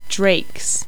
Pronunciation: Draik - drake , Draiks - drakes